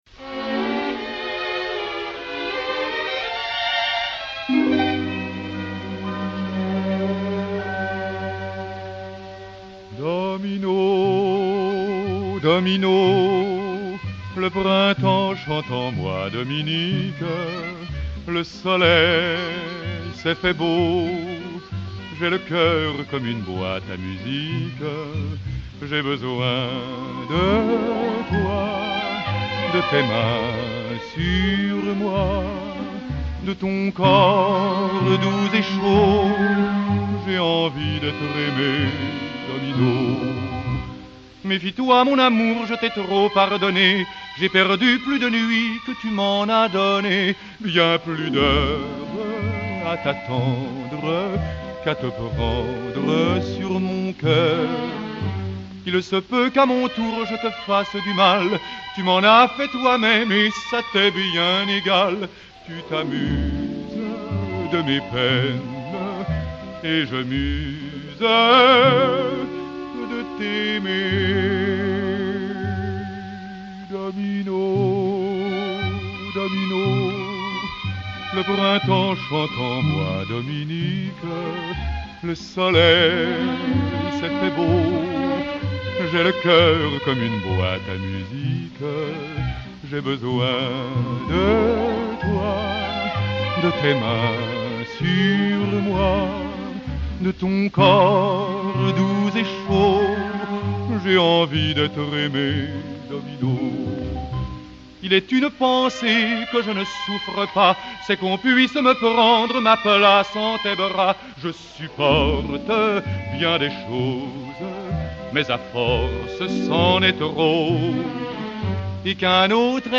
Вальс-мюзет
вальса-мюзет